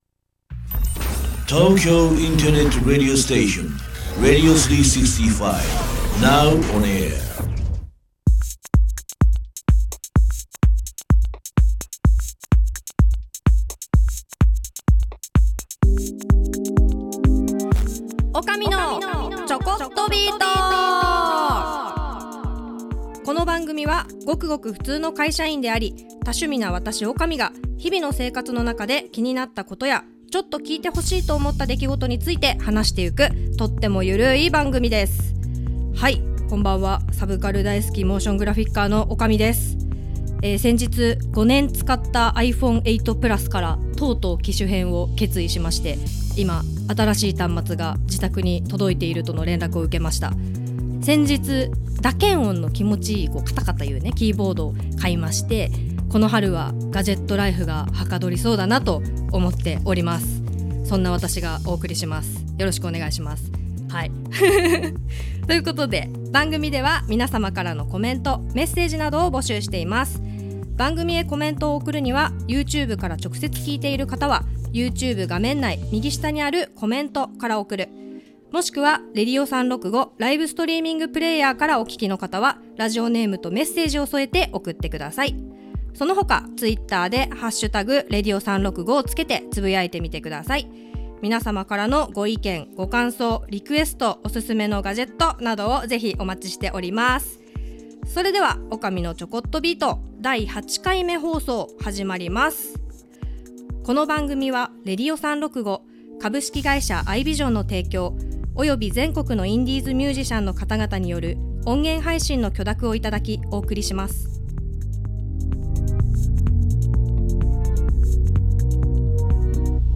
Vol.08～2023年3月19日生放送アーカイブ